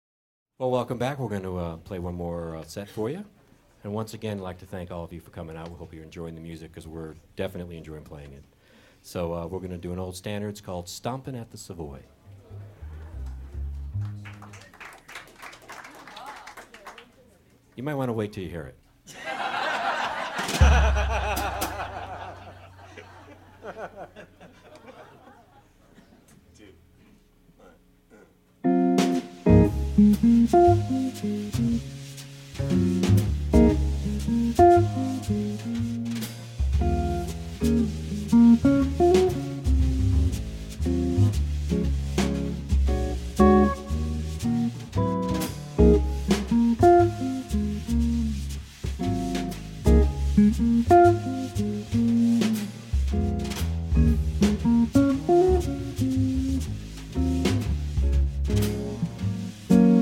A variety of jazz styes and performers on one CD